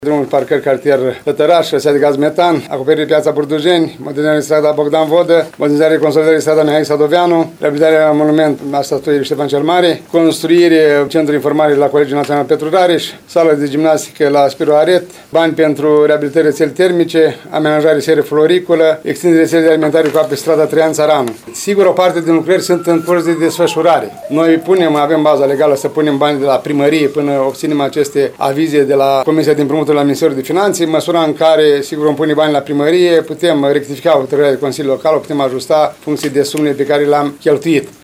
Primarul ION LUNGU a detaliat astăzi investițiile care vor fi realizate cu acești bani.